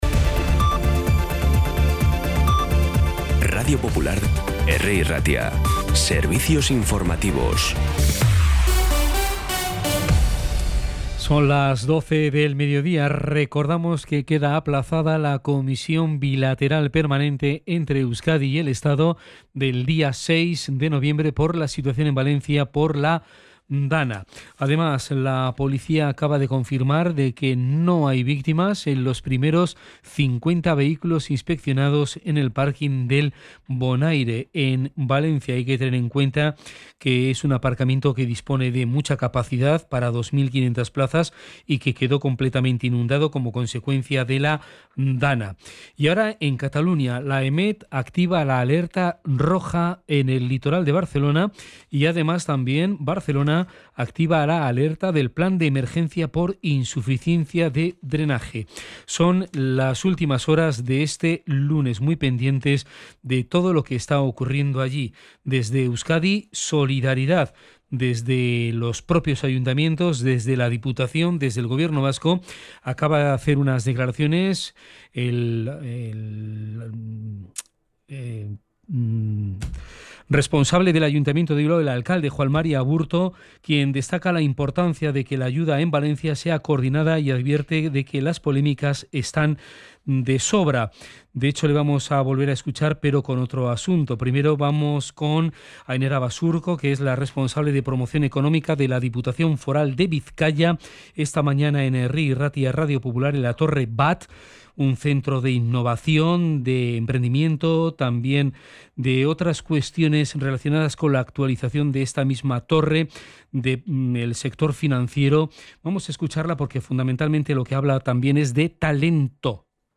Las noticias de Bilbao y Bizkaia del 4 de noviembre a las 12